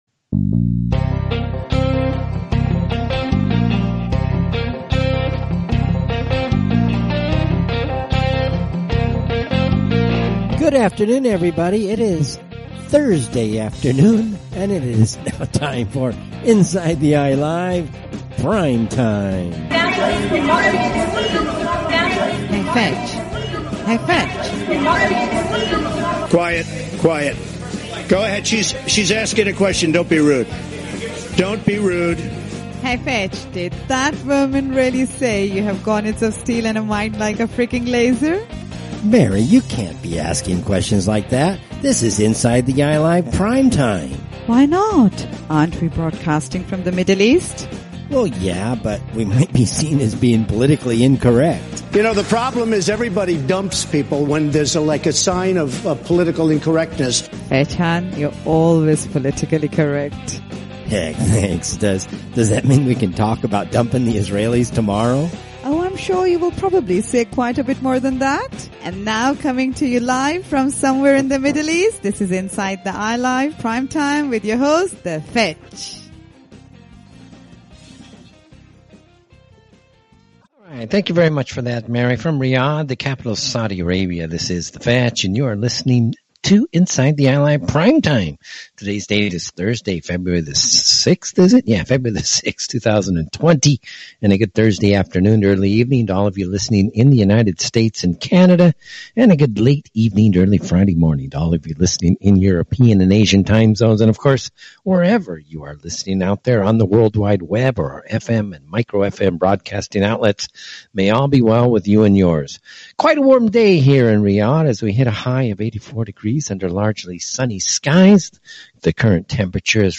Listener calls.